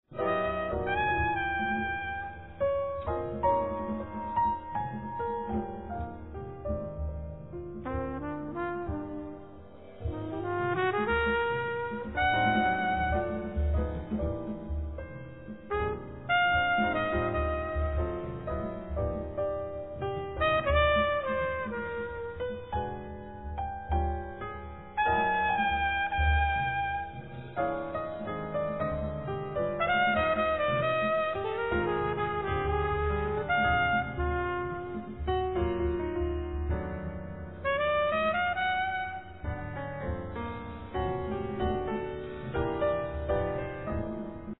Clarinet
Bass
Sax
Trumpet
Drums, Percussions